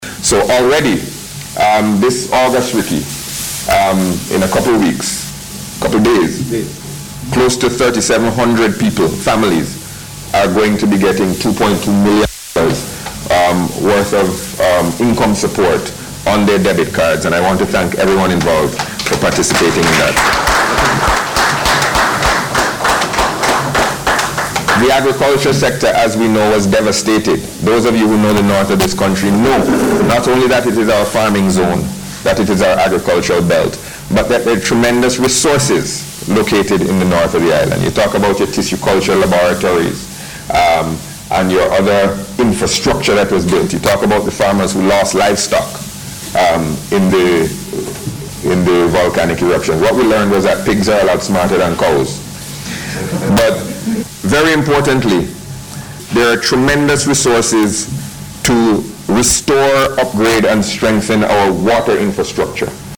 This was disclosed by Minister of Finance and Economic Planning Camillo Gonsalves, during the launch of the Volcanic Eruption Emergency Project (VEEP).